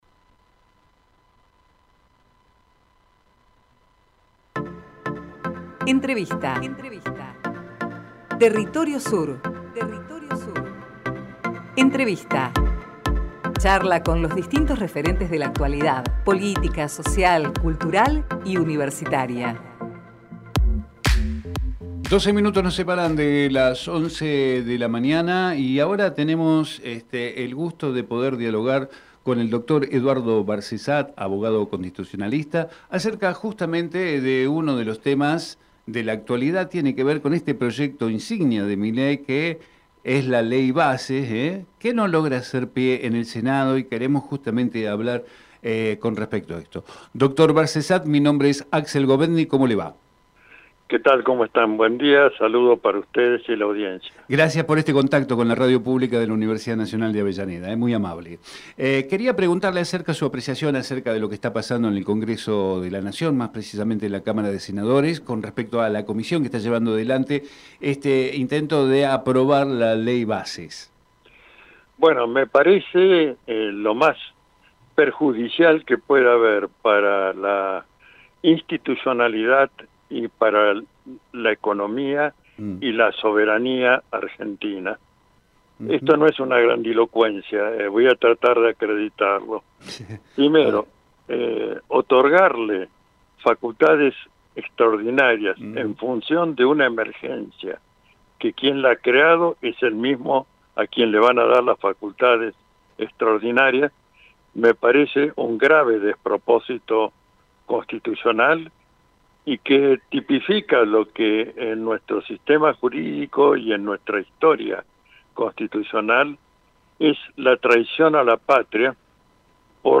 TERRITORIO SUR - EDUARDO BARCESAT Texto de la nota: Compartimos entrevista realizada en "Territorio Sur" con Eduardo Barcesat abogado constitucionalista. charlamos sobre Ley base y Facultades delegadas al presidente Archivo de audio: TERRITORIO SUR - EDUARDO BARCESAT Programa: Territorio Sur